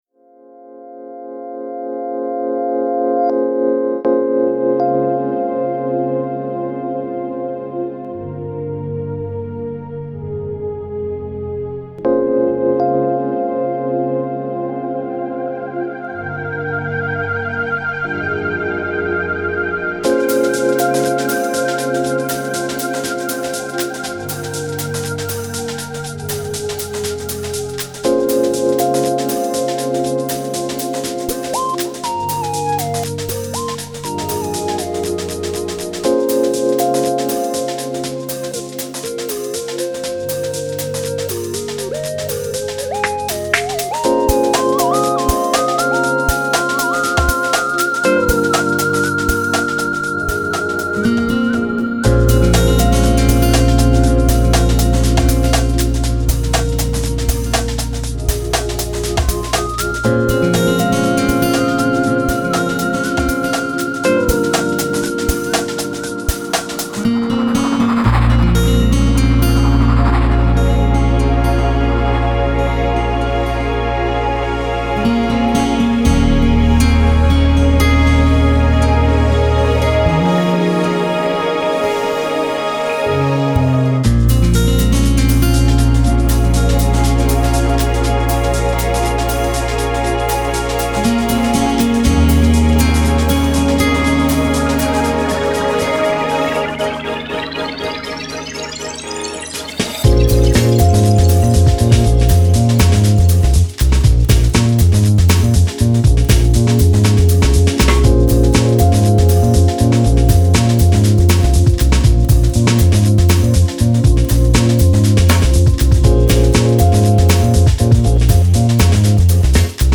Melody for you..Lounge..